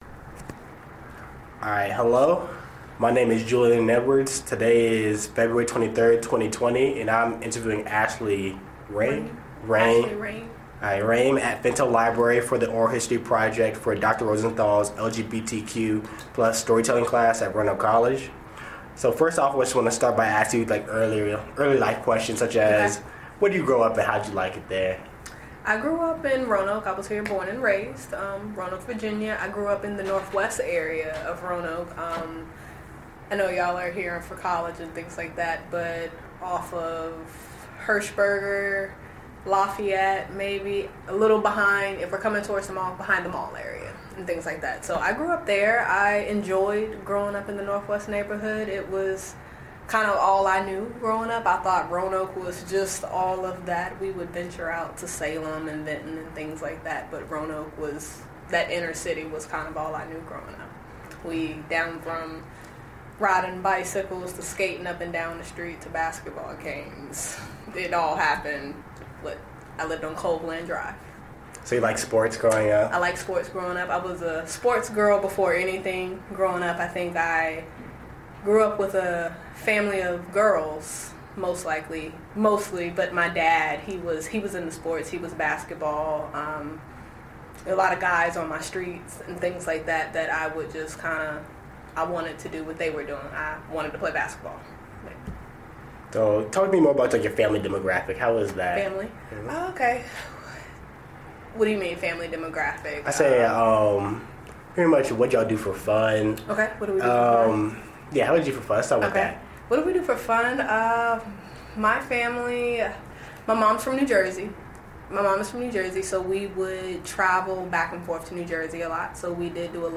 Oral History Interview
Location: Fintel Library, Roanoke College, Salem, Virginia